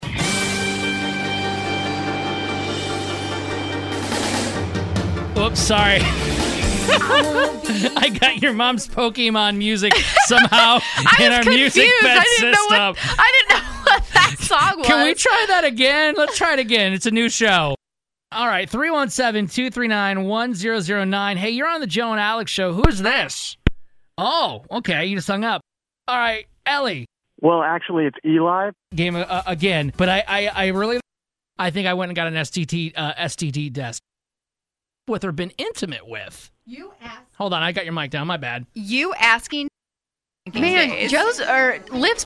Today’s Show Bloopers - 9/13/18
We're broadcasting professionals but we're not perfect...